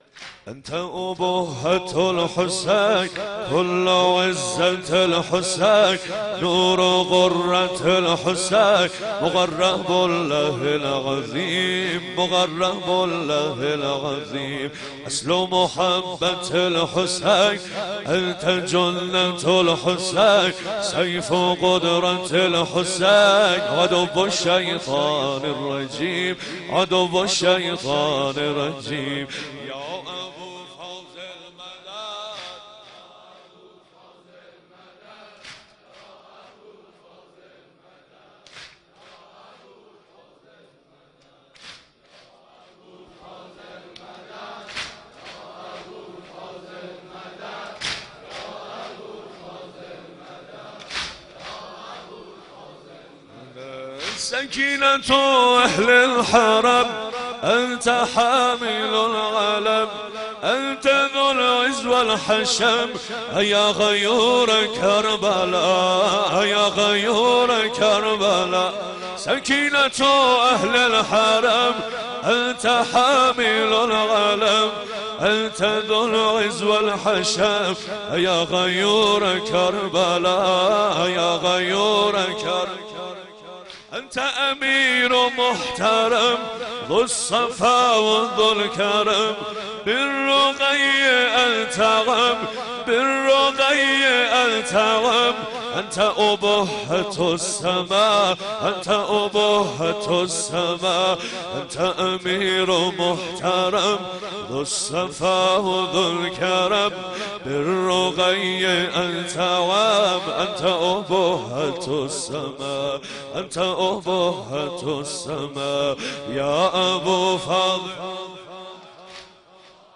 سنگین | أنت اُبهت الحسين كلُ عزت الحسين
مداحی
در مراسم عزاداری شهادت امام حسین (علیه السلام)